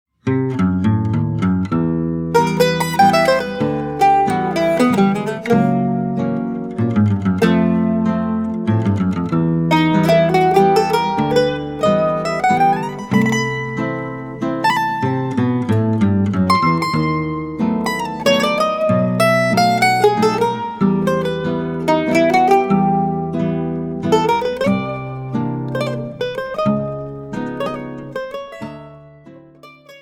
mandolin